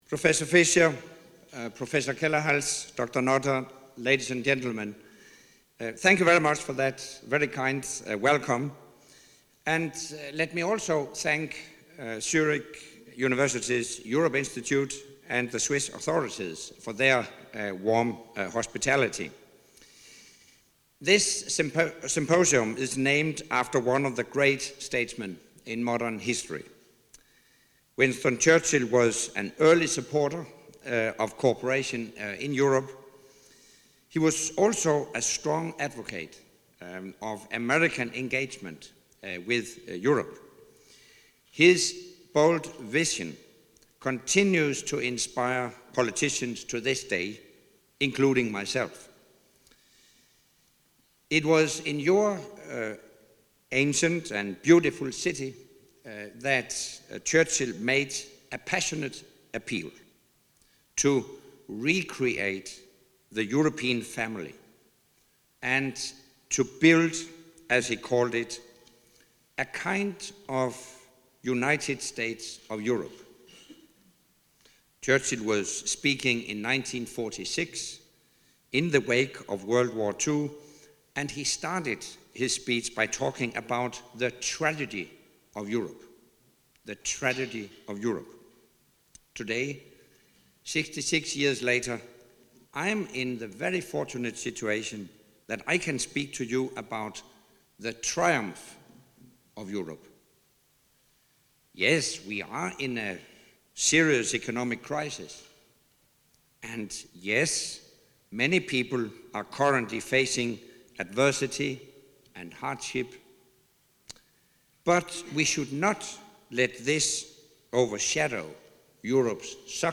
Speech by NATO Secretary General Anders Fogh Rasmussen at the Churchill Symposium in Zürich, Switzerland